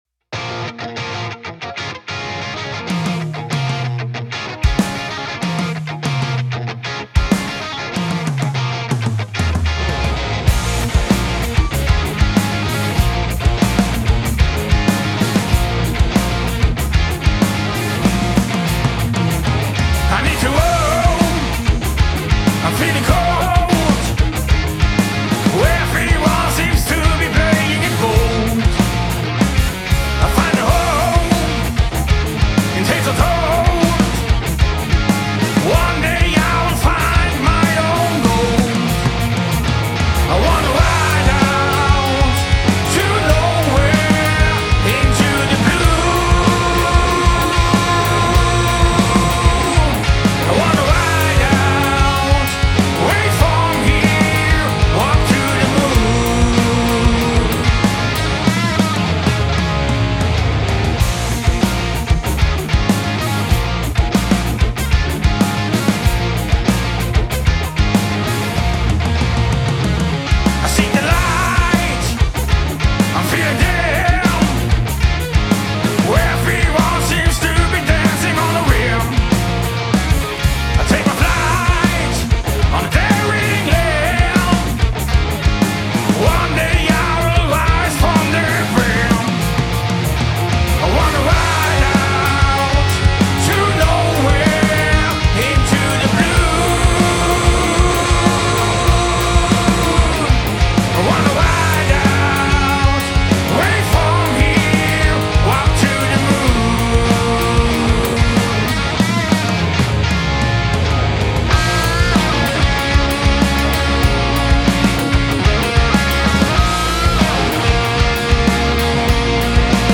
Ride Out - Knackiger Rock song
Mein mix ist noch am Anfang und dient hier eher als Soundbeispiel.
Die Spuren zum Mischen gibt es hier: Google Drive DI Tracks 95 BPM Drums aus EZ Drummer Amps via NeuralDSP Plugin. your_browser_is_not_able_to_play_this_audio